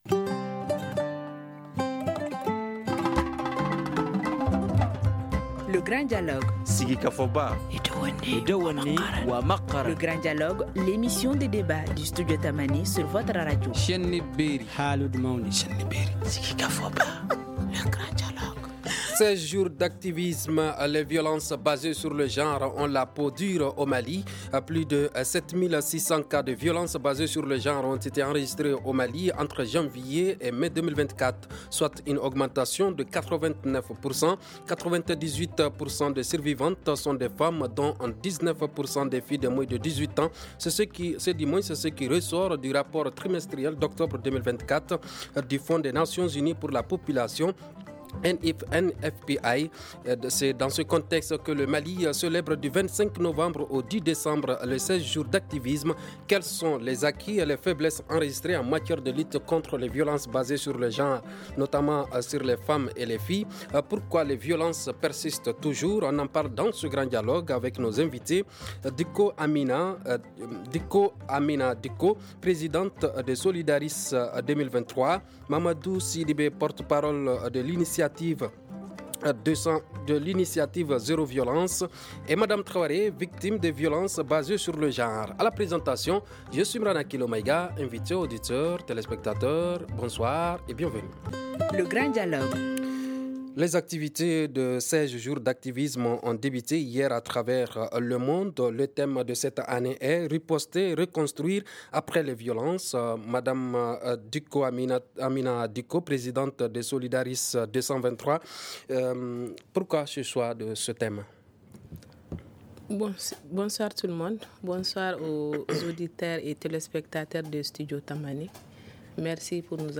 On en parle dans ce Grand Dialogue avec nos invités :